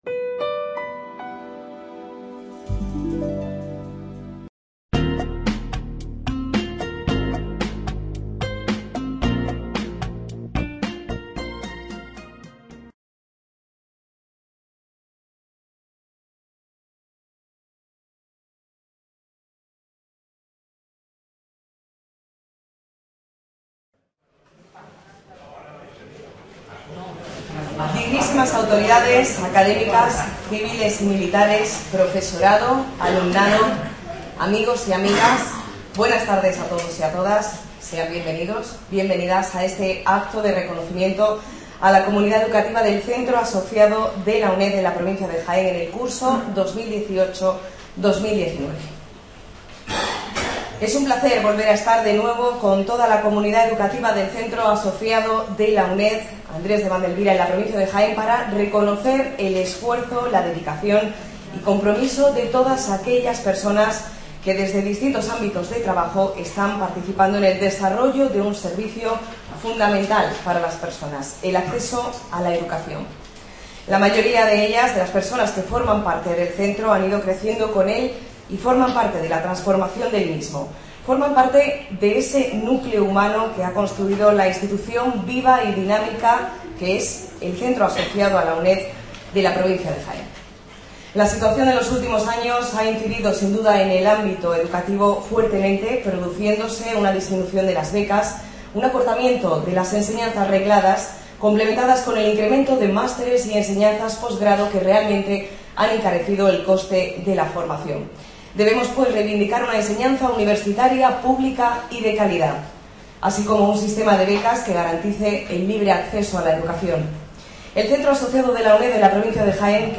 Acto de Reconocimiento a la Comunidad Educativa del Centro Asociado de la UNED de la provincia de Jaén. Curso 2018-19 Description El Centro Asociado de la UNED “Andrés de Vandelvira” de la provincia de Jaén celebra al final de cada curso académico en su sede de Úbeda un Acto de Reconocimiento a la Comunidad Educativa, en el que se premia la labor llevada a cabo por integrantes de los diferentes estamentos que conforman la comunidad del Centro Asociado.